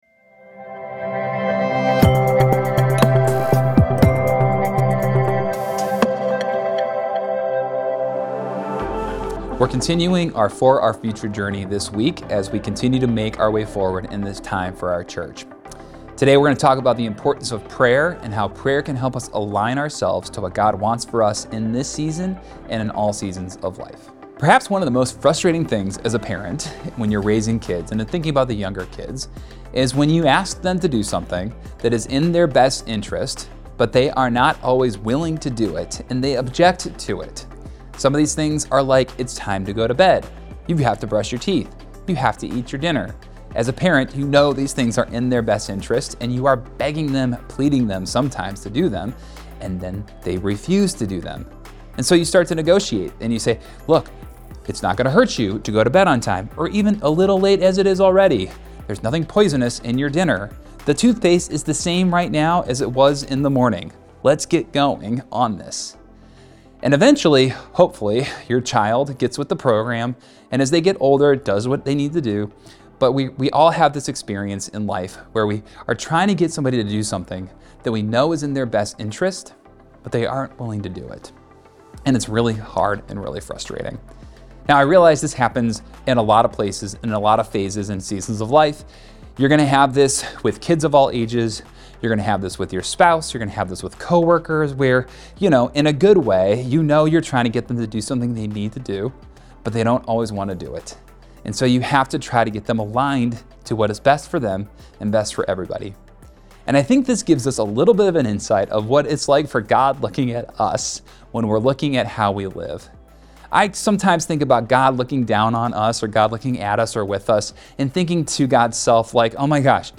Sunday, October 27, 2024 – 10am Online Service – Week 3 of For Our Future